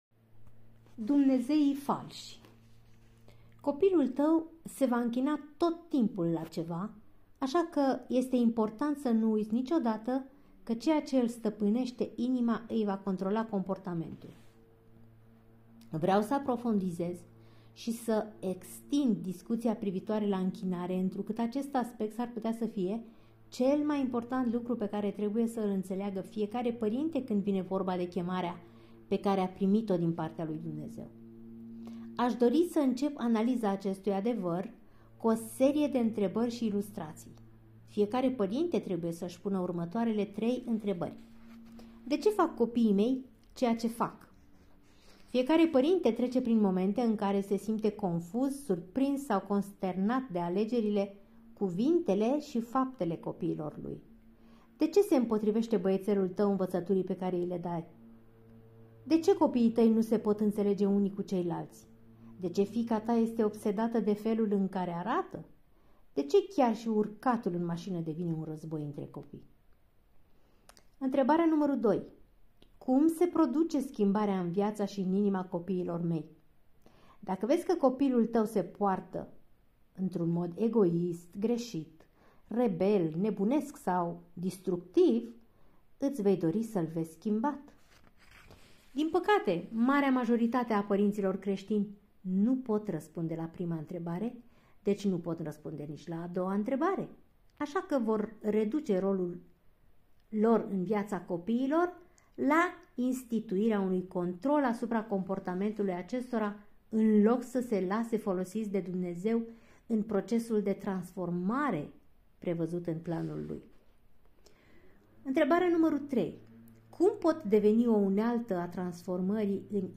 Capitolul este citit